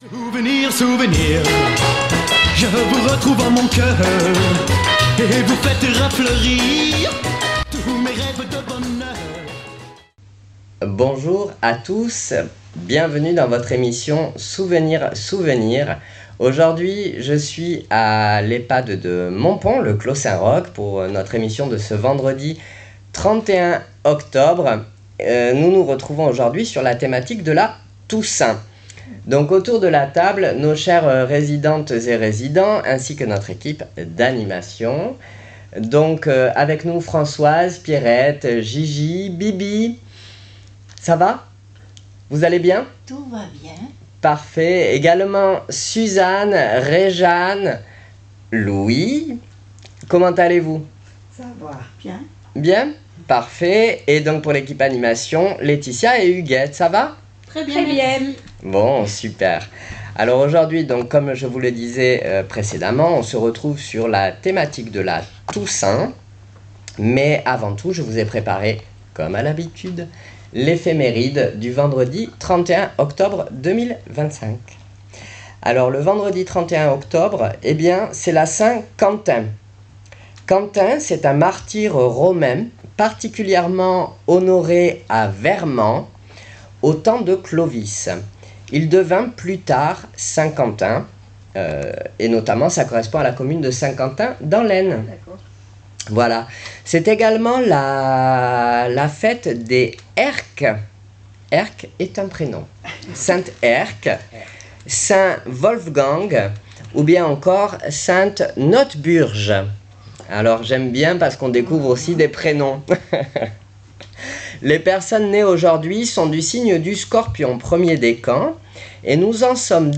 Souvenirs Souvenirs 31.10.25 à l'Ehpad de Montpon " La toussaint "